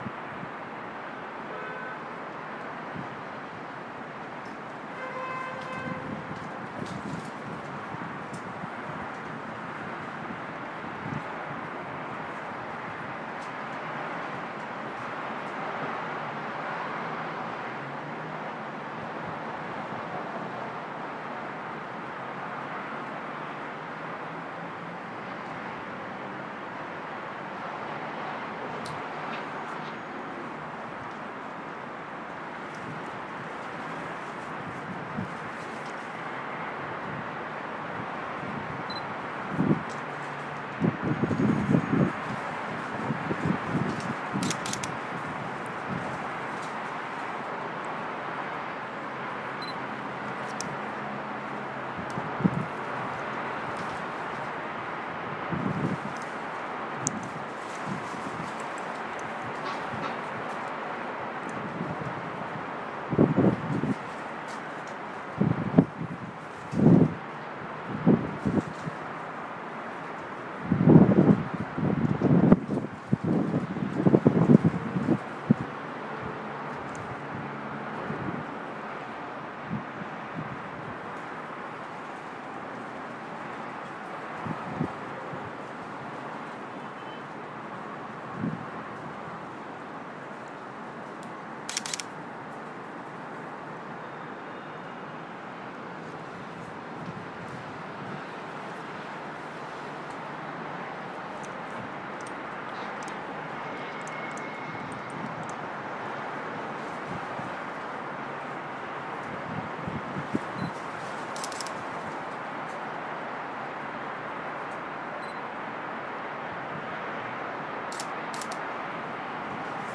Ambience: Windy City Streets
The nighttime sounds if city centre streets on a windy evening in Birmingham, England.